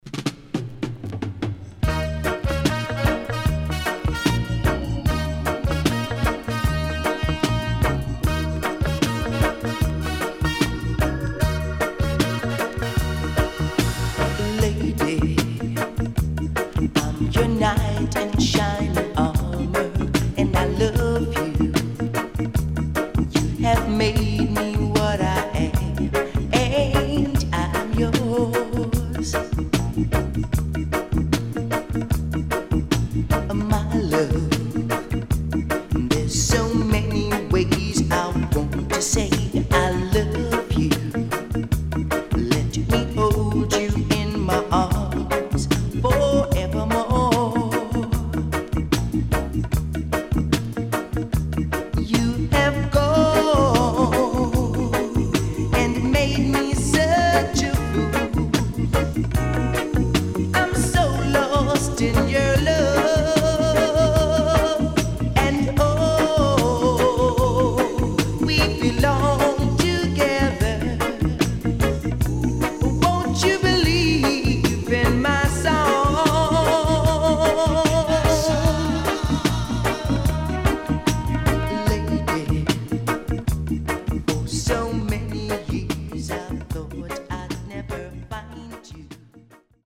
Wicked.A:後半Deejay接続.B:後半Sax Cut接続